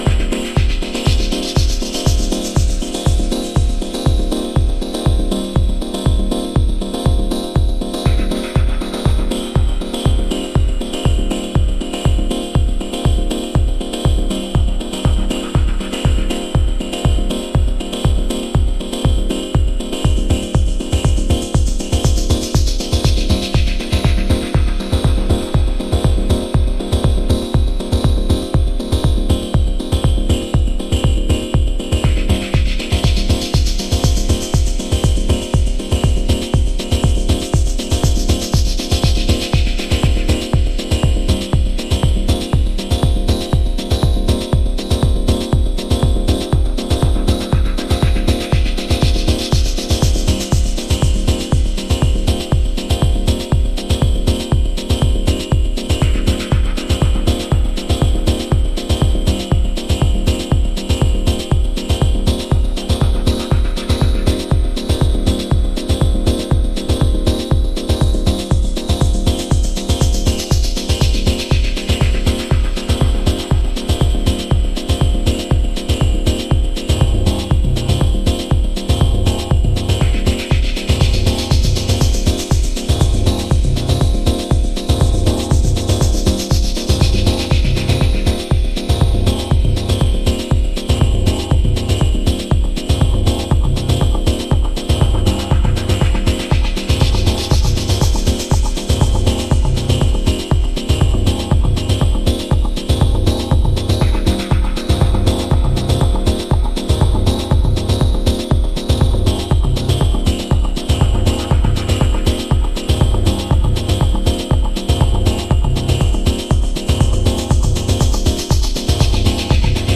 低いところをフローティングするのに持って来いの2トラック。